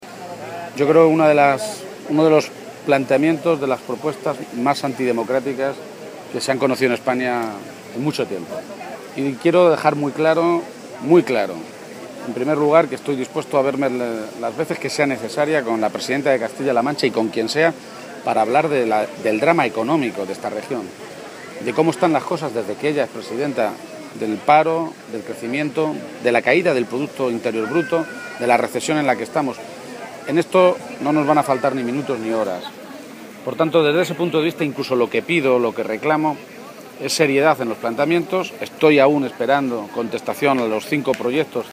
García-Page se ha pronunciado así en Puertollano, localidad a la que ha acudido para participar en sus fiestas patronales, un escenario en el que ha criticado el afán de Cospedal por querer acabar con la política o desprestigiarla, quizá, ha sugerido, porque “probablemente en toda España va a costar encontrar a alguien con menos capacidad de ejemplo de cómo se debe hacer política como la señora Cospedal”.